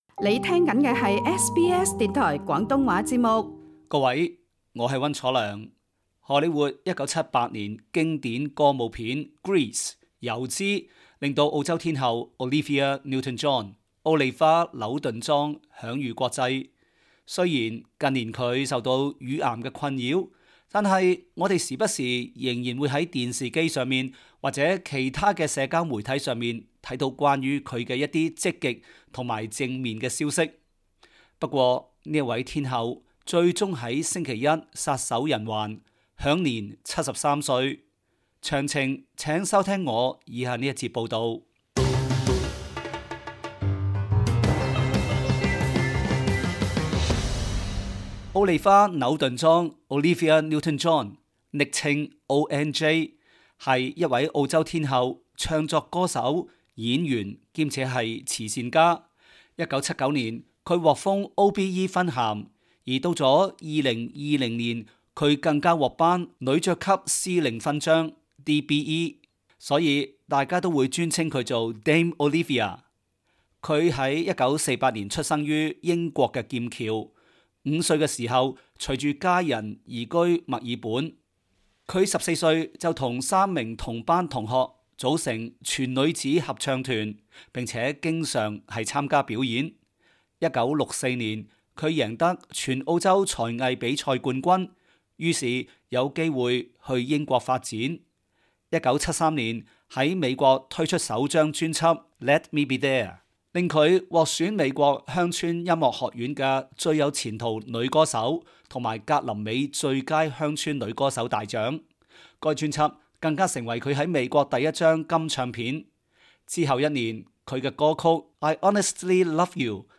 「時事報道」